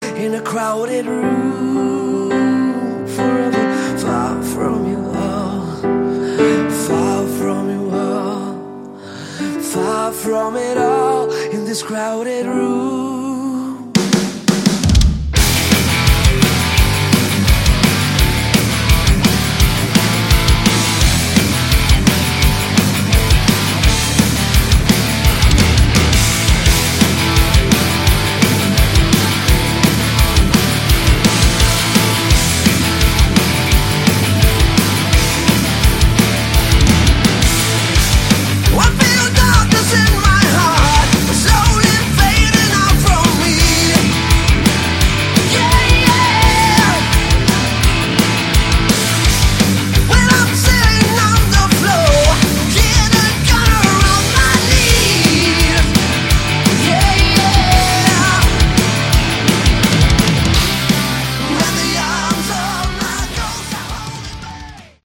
Category: Hard Rock
vocals
bass
guitars
drums